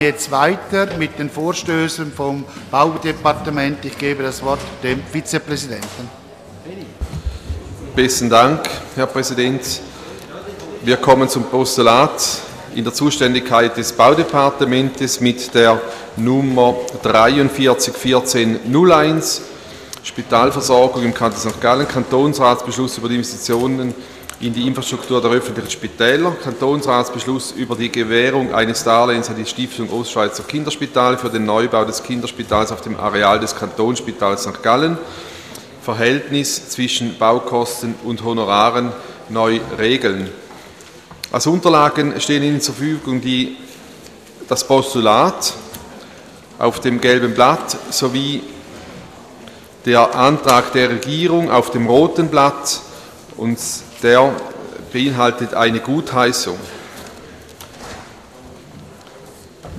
Ratsvizepräsident: stellt Eintreten auf das Postulat fest.